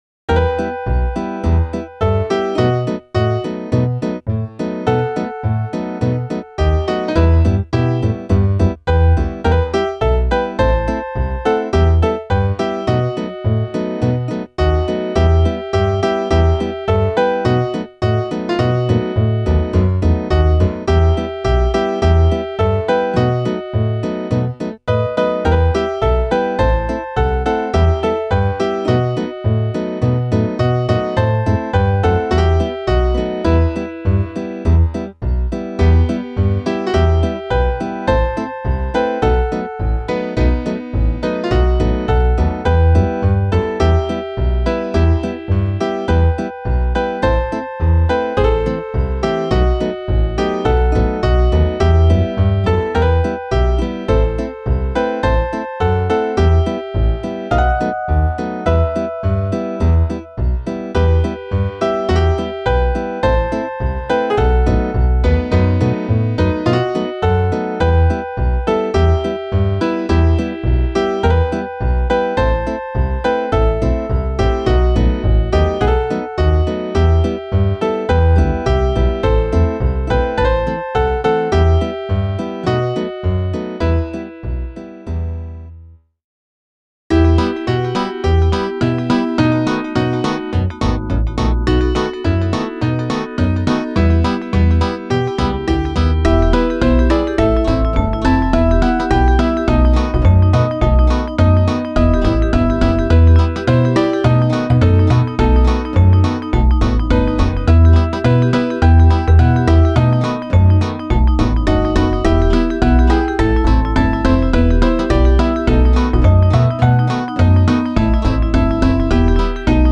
Variationen